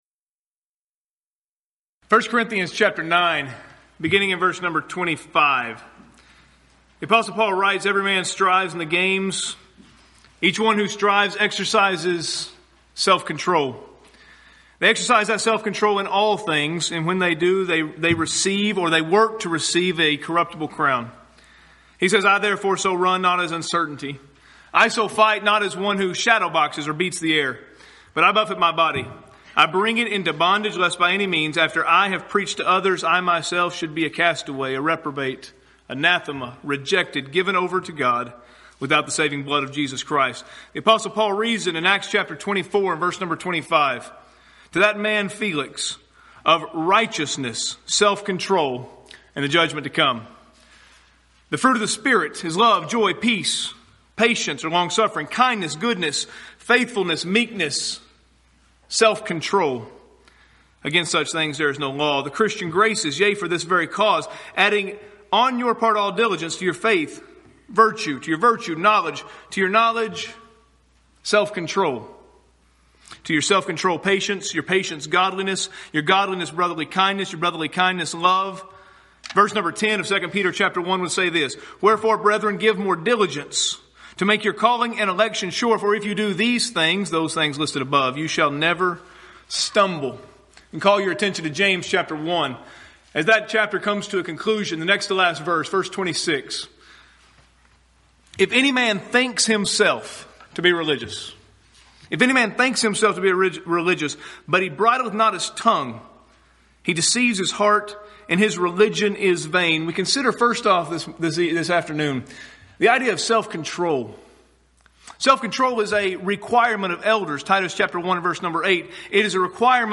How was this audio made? Event: 13th Annual Schertz Lectures